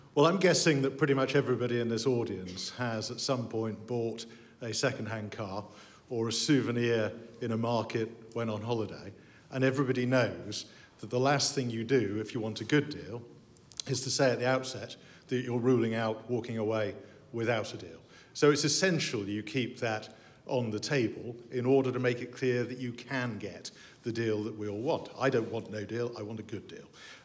A short practical lesson in equivocation by Sir Graham Brady, Chairman of the 1922 Committee.